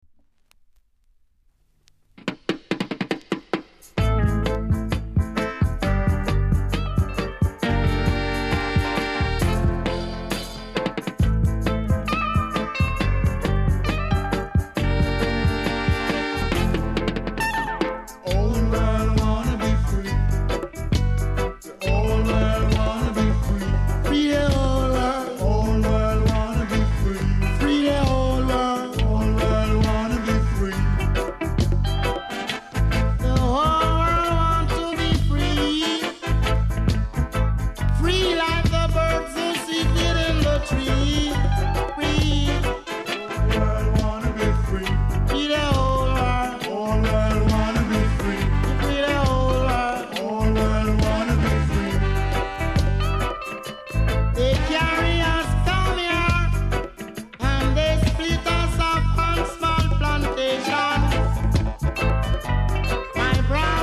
コメント ROOTS CLASSIC!!このUK REISSUE盤もレアです!!